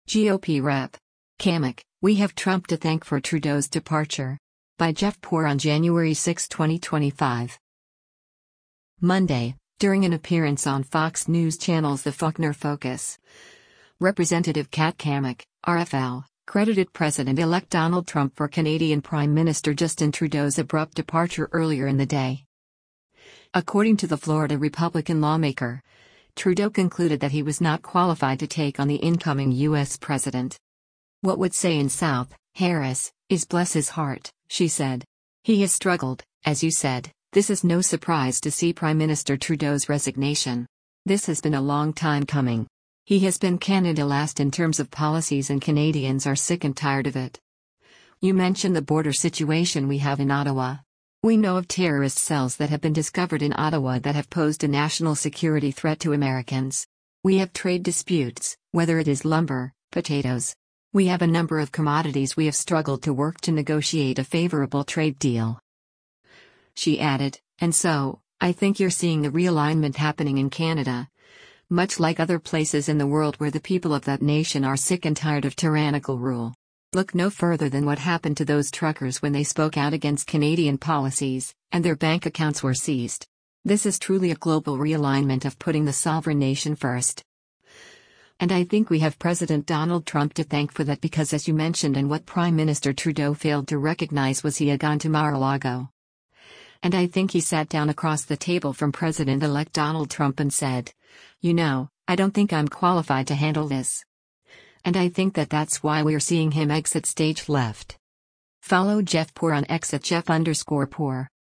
Monday, during an appearance on Fox News Channel’s “The Faulkner Focus,” Rep. Kat Cammack (R-FL) credited President-elect Donald Trump for Canadian Prime Minister Justin Trudeau’s abrupt departure earlier in the day.